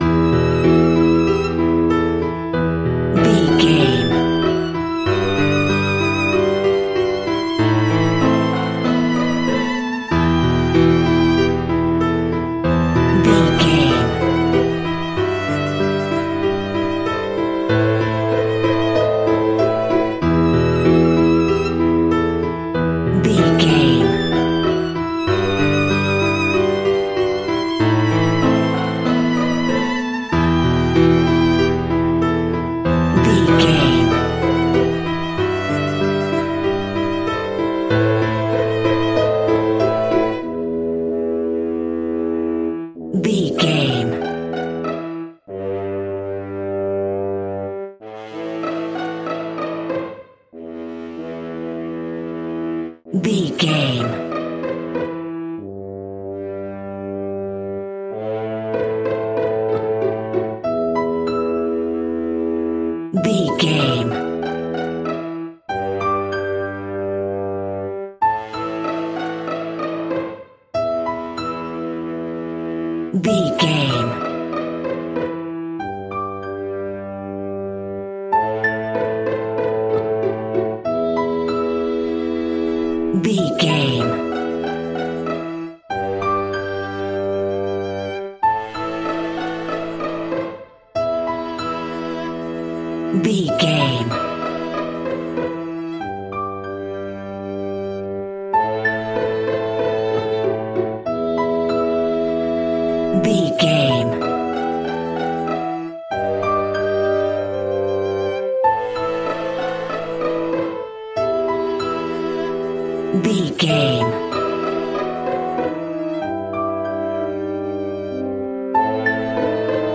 Aeolian/Minor
scary
ominous
dark
suspense
eerie
piano
strings
violin
horror
synthesizers
Synth Pads
atmospheres